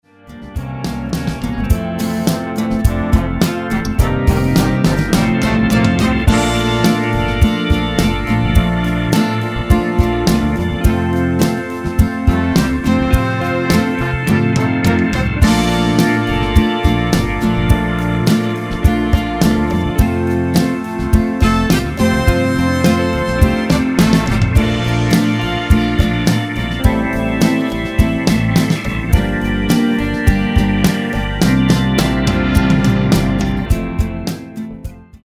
--> MP3 Demo abspielen...
Tonart:F ohne Chor